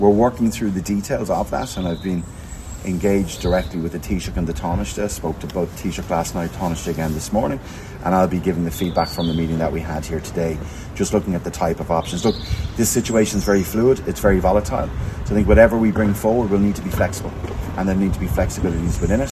Transport Minister Darragh O’Brien says he is committied to bringing the measures to Cabinet next week………………..